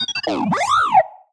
happy6.ogg